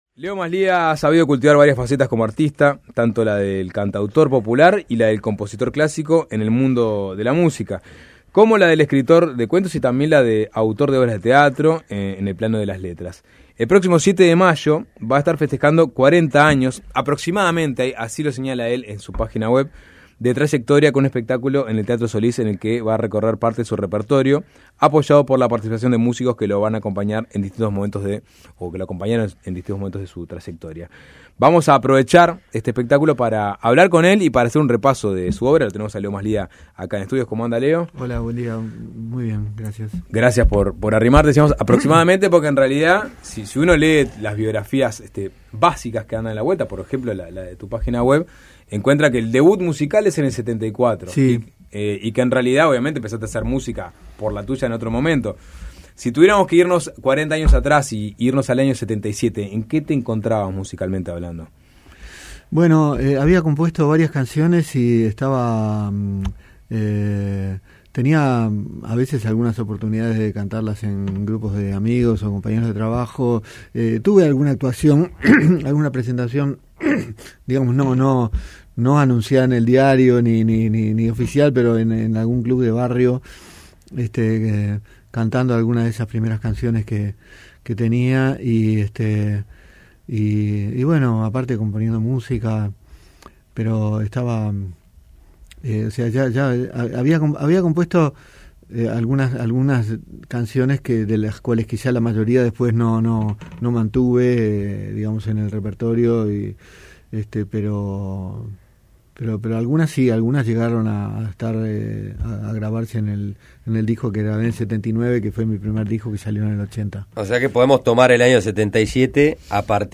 Entrevista en Suena Tremendo